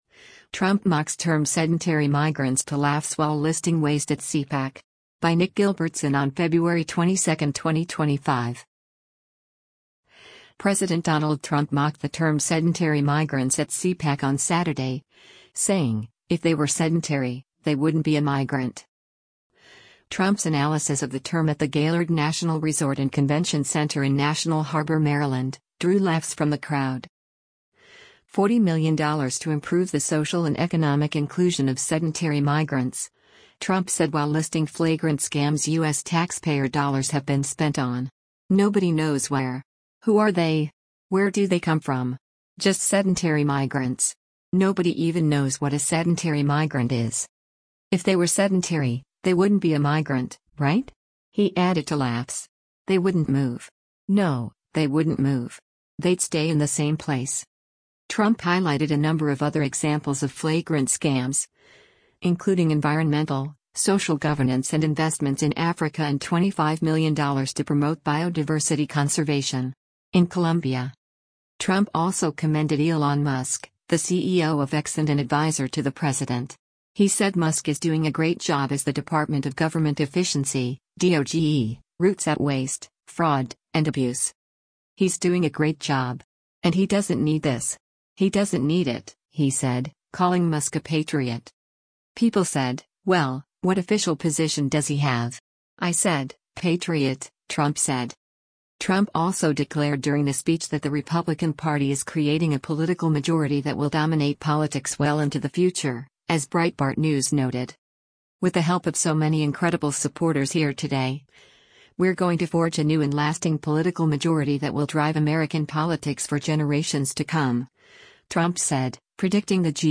Trump’s analysis of the term at the Gaylord National Resort & Convention Center in National Harbor, Maryland, drew laughs from the crowd.